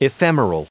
290_ephemeral.ogg